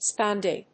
音節spon・dee 発音記号・読み方
/spάndiː(米国英語), spˈɔndiː(英国英語)/